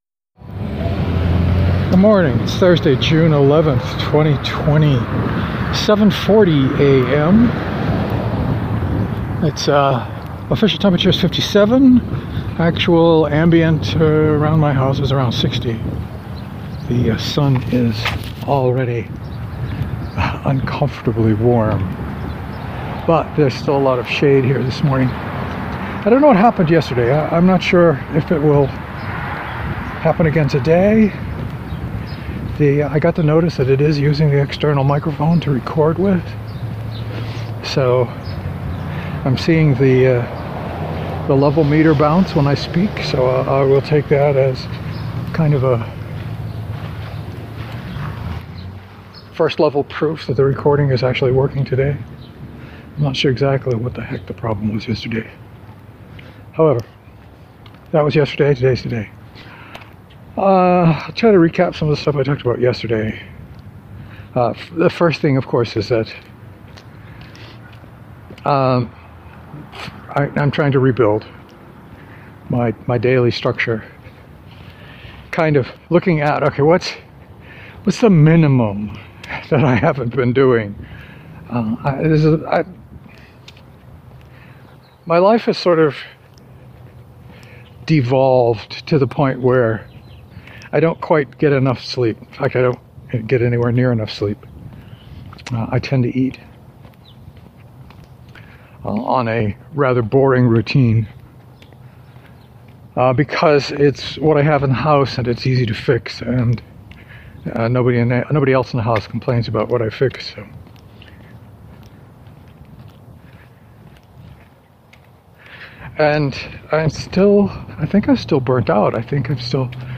The file sounds much better today but I’ve already forgotten what I talked about.